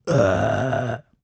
Male_Burp_01.wav